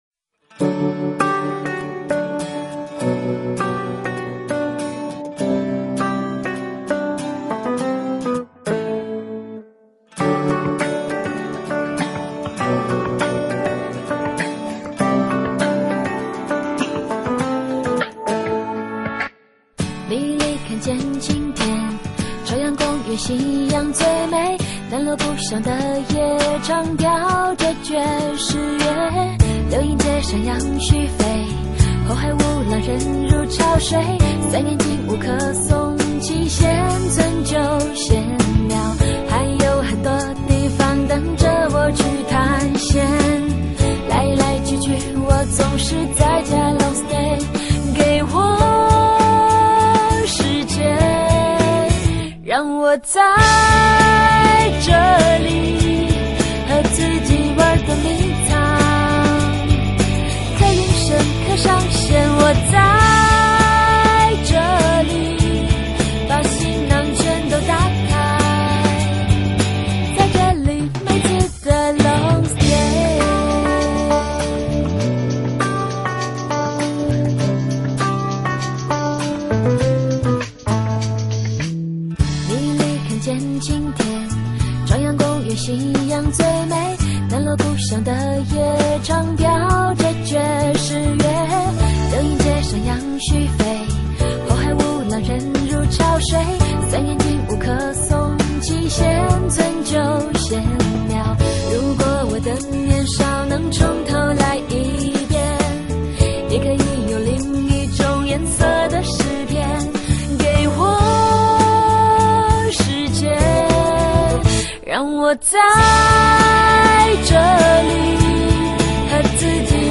风格: 流行